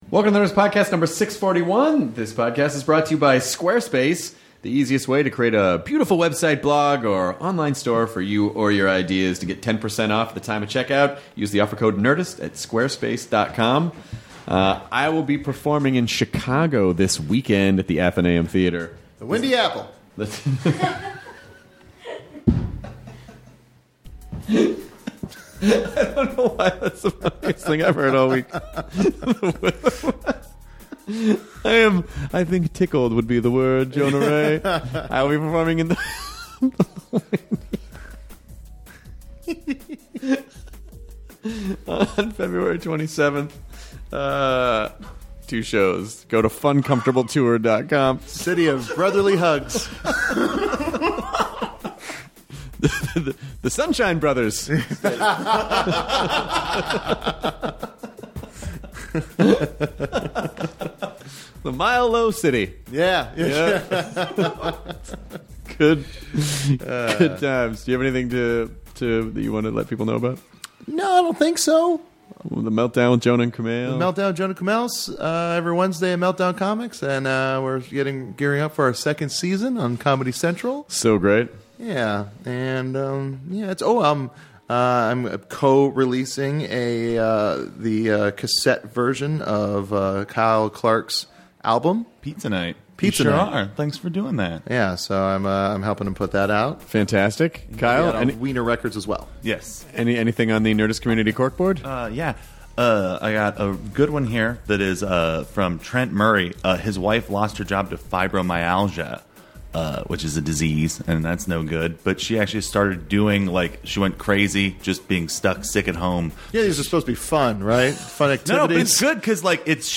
Eric Idle also sits in and talks about how they met, his views on science and they all discuss different theories for what the universe is!